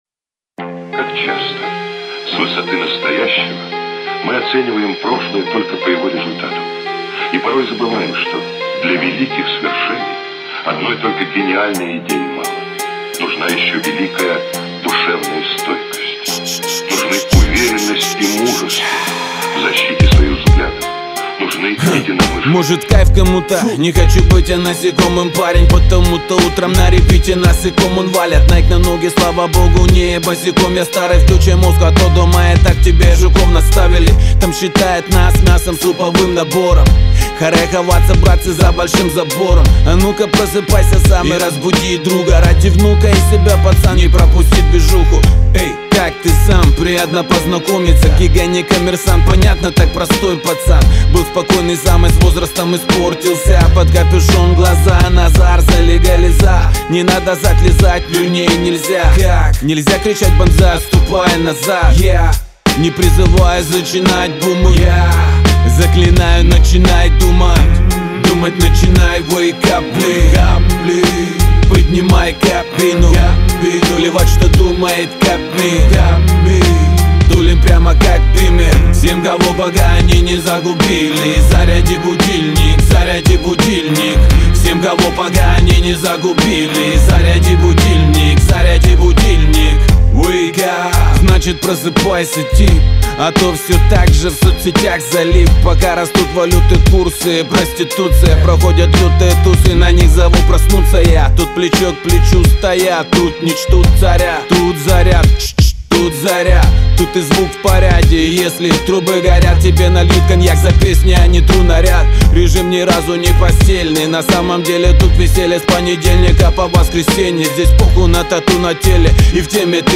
giga_budilnik.mp3